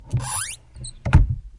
关闭一扇刺耳的门 (3)
描述：被关闭的尖叫的木门的声音。用Roland R05录制。
Tag: 关闭 关闭 尖叫 噪音